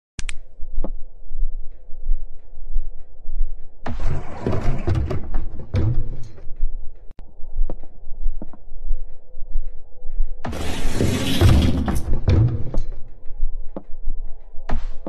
Sound Design
The audio effects for the lever animations are recorded carefully with my phone from simple doors and gates in my own home.